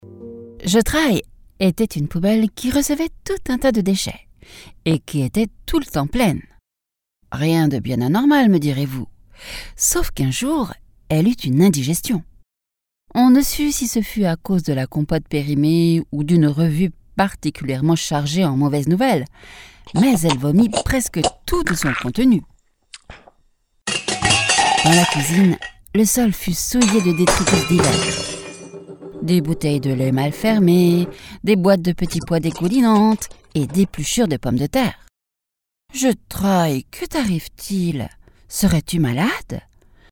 Audiolivros
Minha voz é calorosa, feminina e adequada para narração, elearning, audiolivros, audioguias, mas também alguns comerciais, jogos.
Micro Neumann 103
Uma cabine Isovox em uma cabine à prova de som